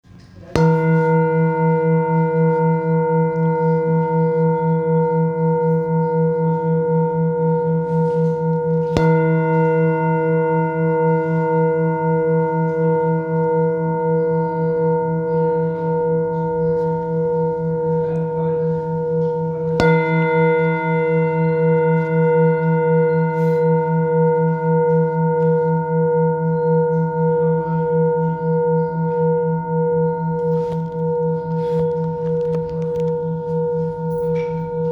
Singing Bowl, Buddhist Hand Beaten, with Fine Etching Carving, Samadhi, Select Accessories
Material Seven Bronze Metal